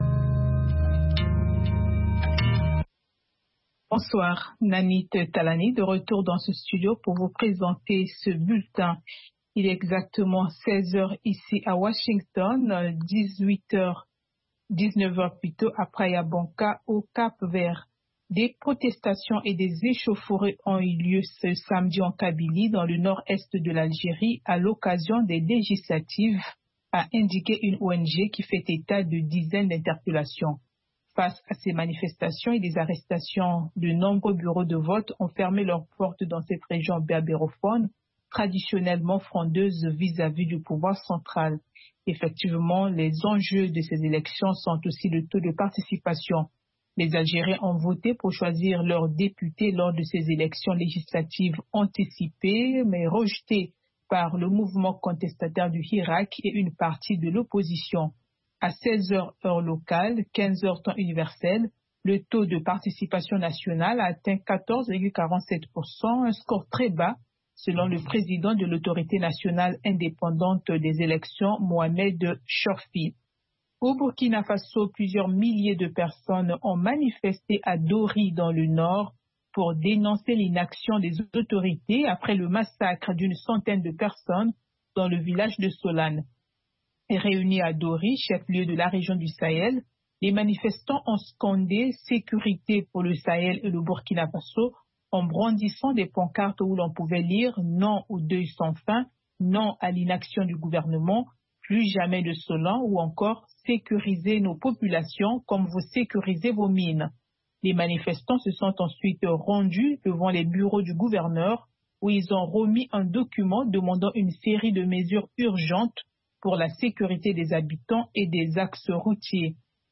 RM Show - R&B et Rock